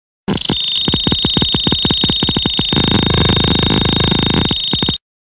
>こういう画像見ると毎回北斗の拳の効果音が頭に再生される